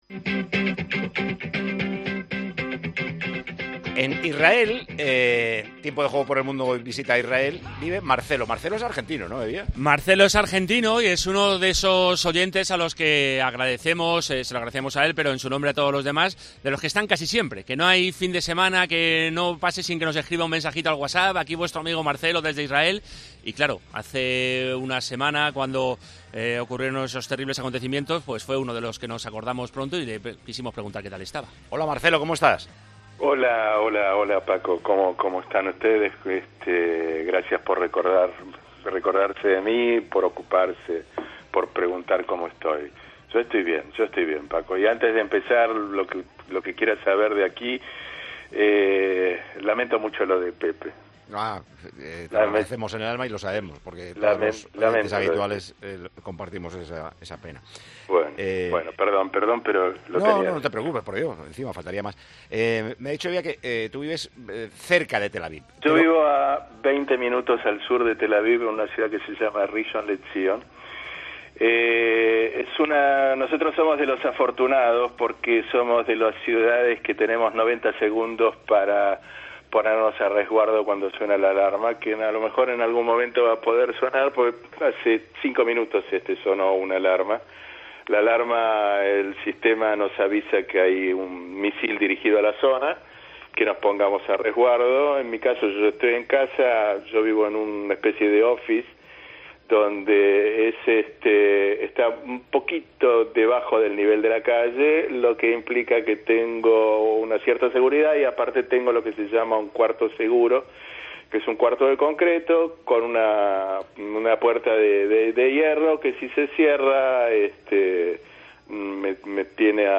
Este domingo, Paco González charló con uno de nuestros oyentes por el mundo que más pendiente está de